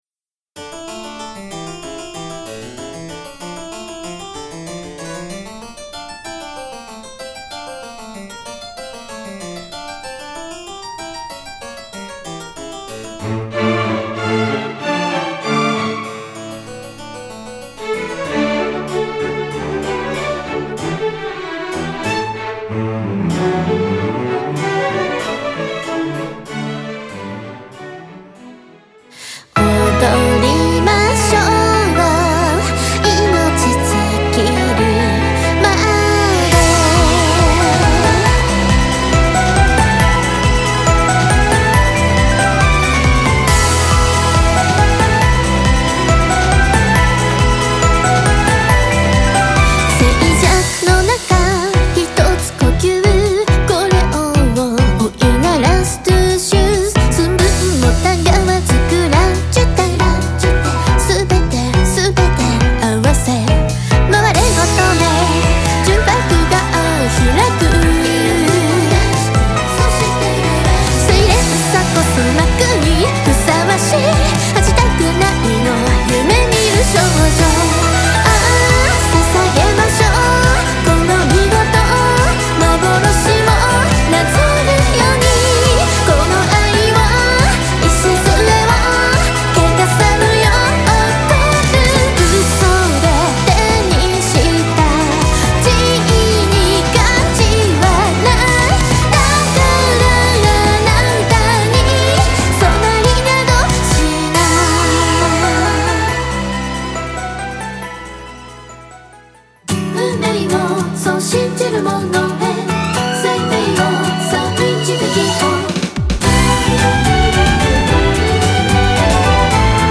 - Instrumental Version -
Guitar